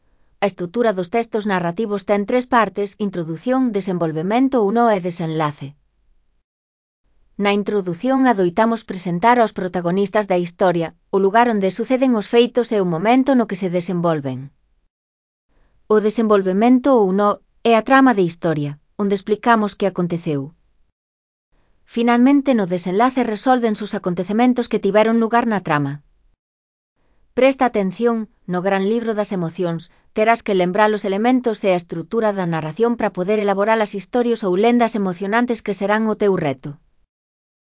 Elaboración propia (Proxecto cREAgal) con apoio de IA, voz sintética xerada co modelo Celtia. . Partes dos textos narrativos (CC BY-NC-SA)